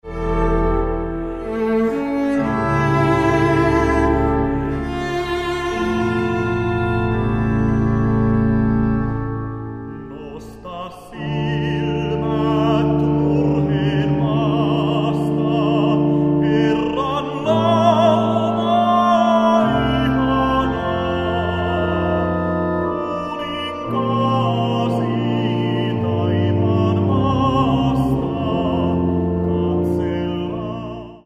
virsiä ja lauluja
laulu